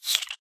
silverfish